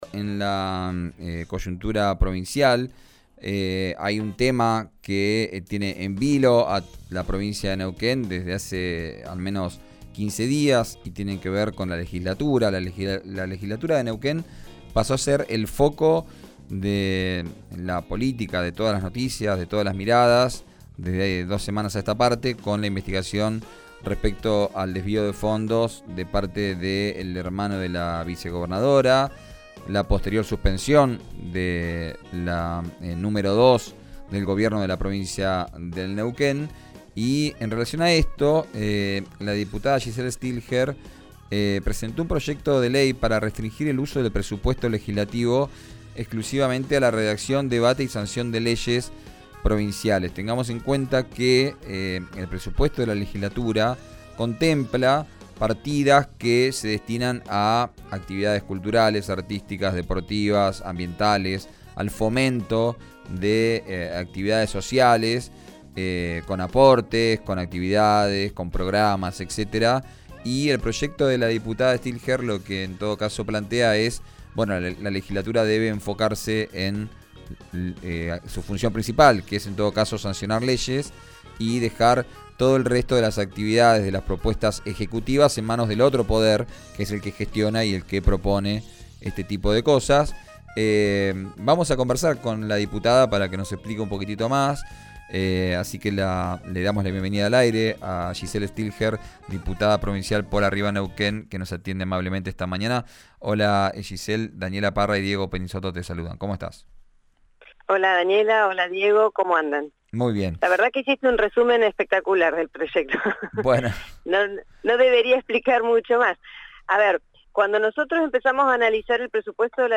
Escuchá a Giselle Stillger, en RADIO RÍO NEGRO: